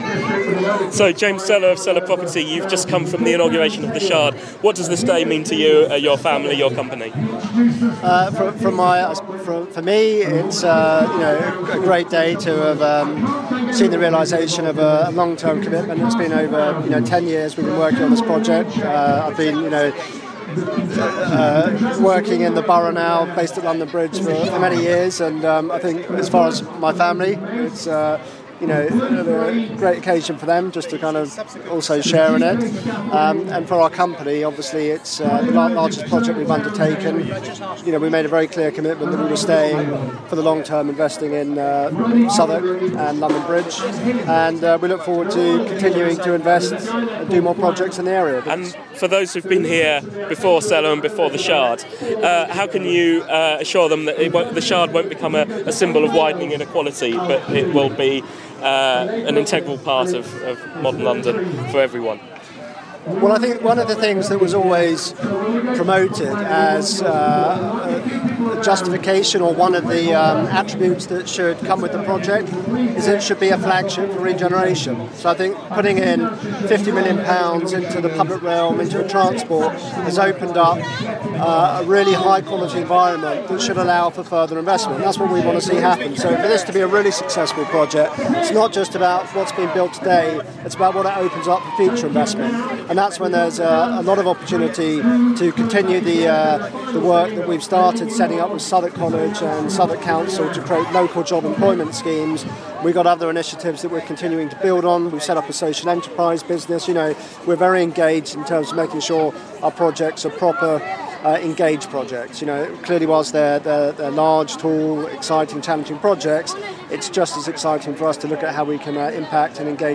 The Shard - exclusive interview